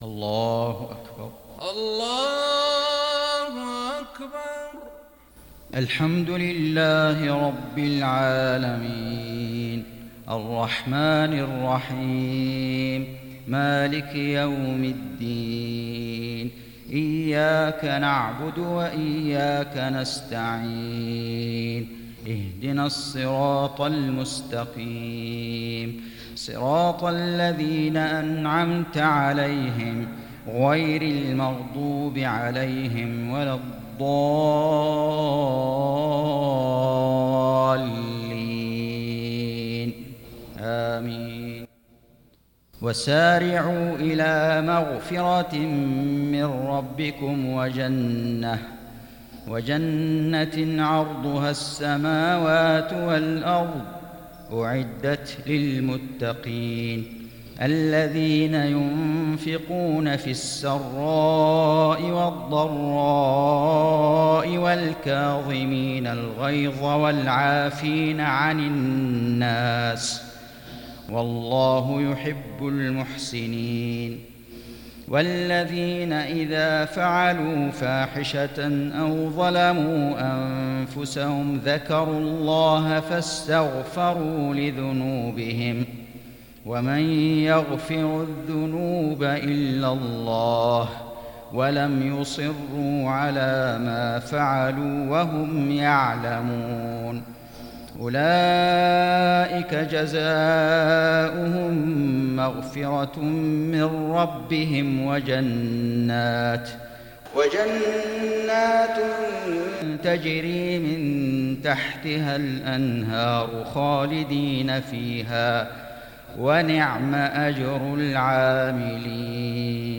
صلاة المغرب للشيخ فيصل غزاوي 8 ذو الحجة 1441 هـ
تِلَاوَات الْحَرَمَيْن .